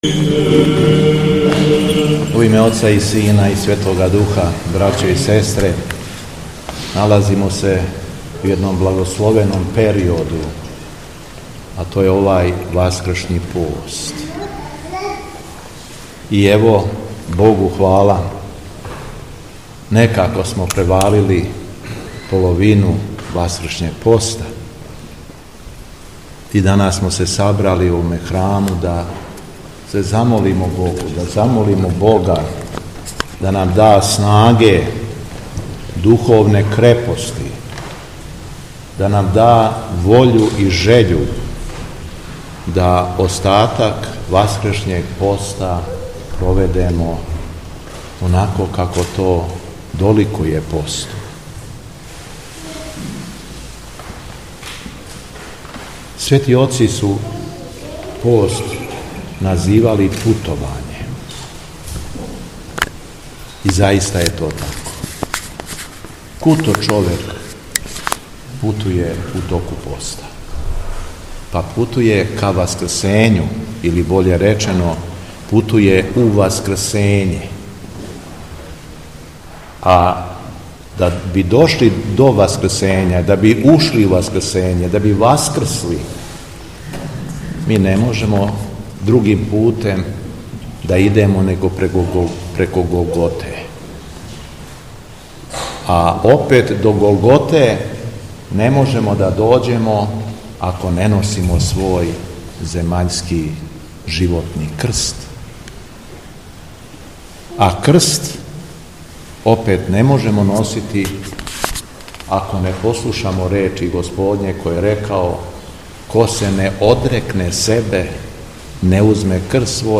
АРХИЈЕРЕЈСКА БЕСЕДА – МИТРОПОЛИТ ШУМАДИЈСКИ Г. ЈОВАН – Бог нам помаже да се ослободимо самих себе
Јер Бог жели да се сви спасем, рекао је, између осталог Високопреподобни Митрополит шумадијски г. Јован на Светој архијерејској литургији у Вранићу.